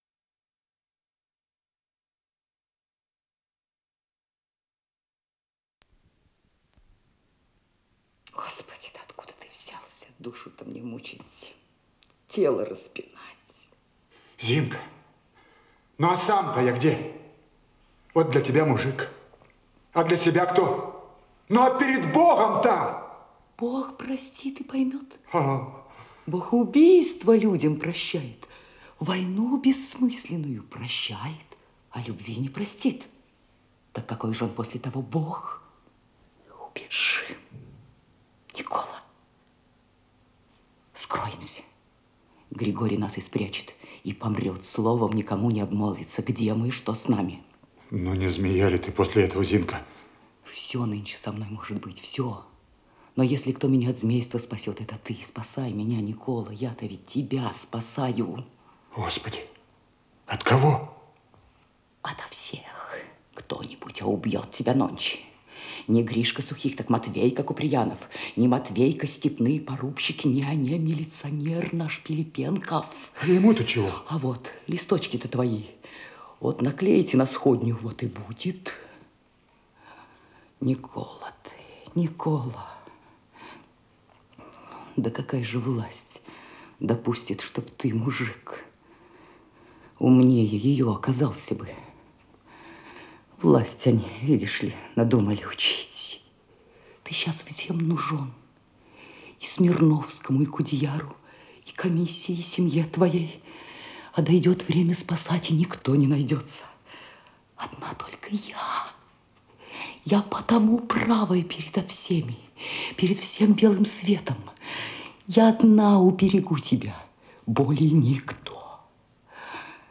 Новосибирский театр "Красный факел"